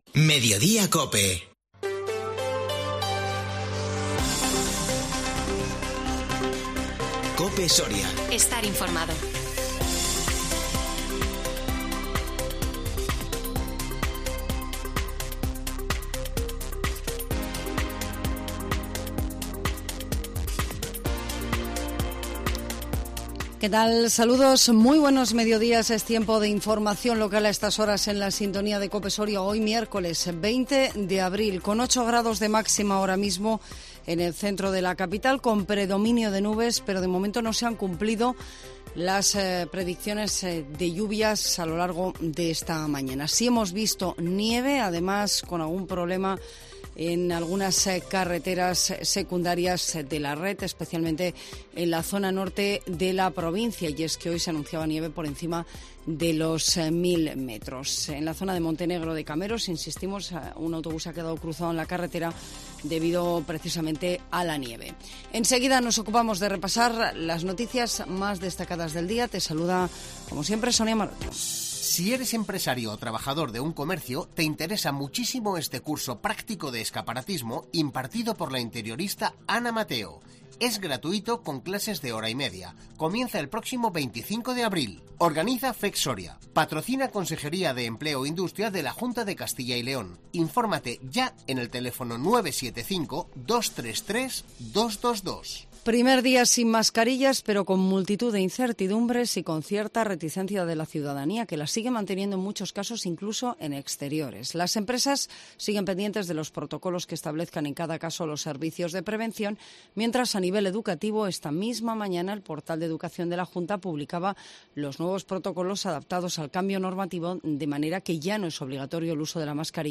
INFORMATIVO MEDIODÍA COPE SORIA 20 ABRIL 2022